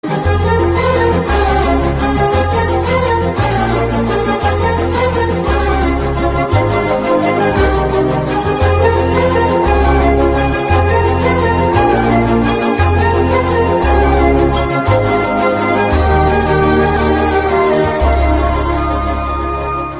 BGM Ringtones